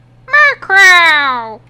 MURKROW.mp3